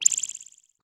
window-minimized.ogg